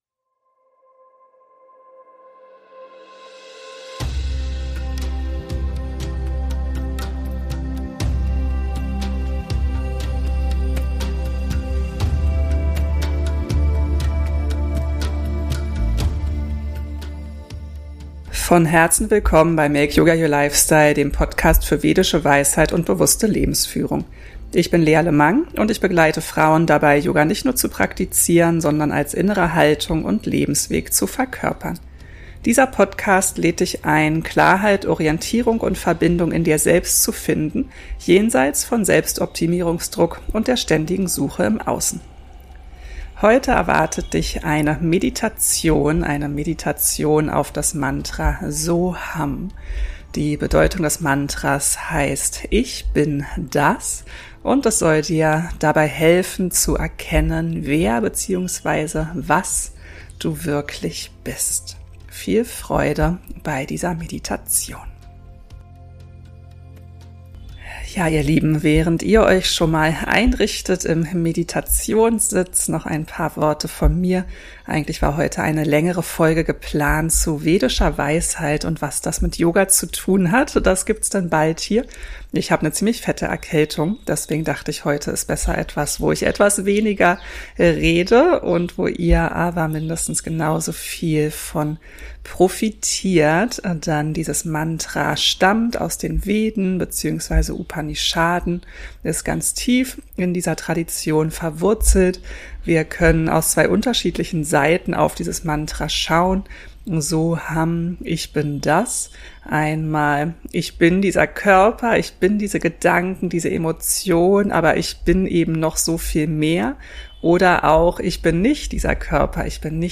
In dieser Folge führe ich dich durch eine So hum-Meditation. Eine einfache und zugleich tief transformierende Praxis, die dich daran erinnert, was dein wahres Wesen ist.